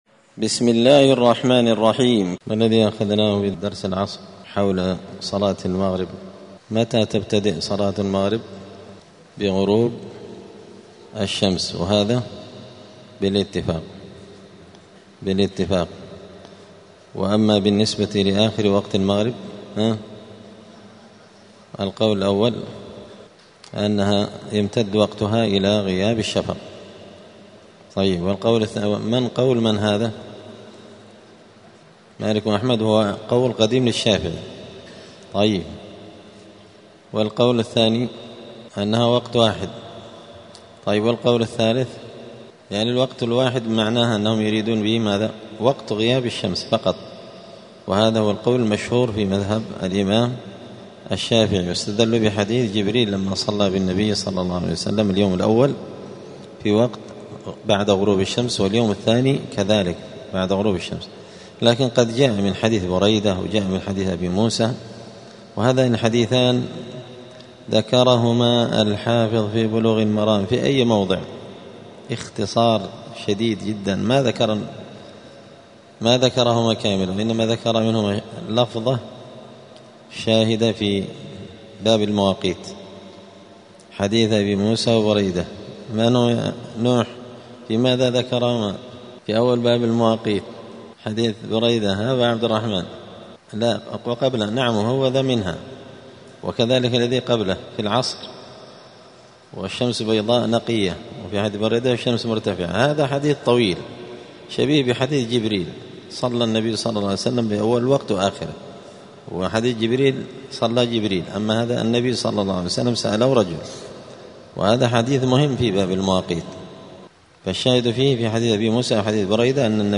دار الحديث السلفية بمسجد الفرقان قشن المهرة اليمن
*الدرس الواحد والثلاثون بعد المائة [131] {وقت العشاء}*